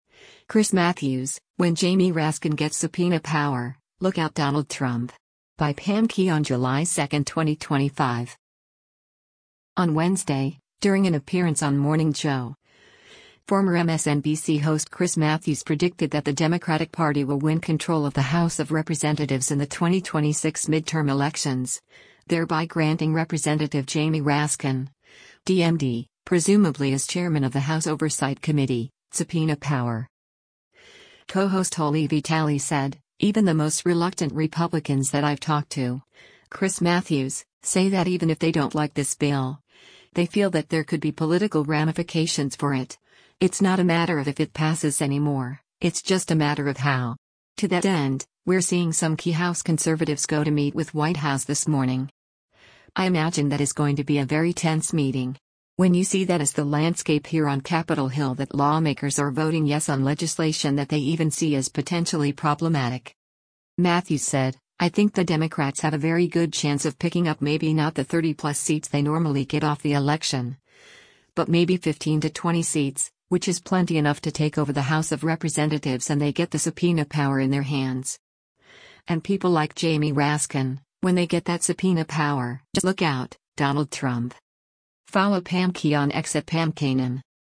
On Wednesday, during an appearance on “Morning Joe,” former MSNBC host Chris Matthews predicted that the Democratic Party will win control of the House of Representatives in the 2026 midterm elections, thereby granting Rep. Jamie Raskin (D-MD), presumably as chairman of the House Oversight Committee, subpoena power.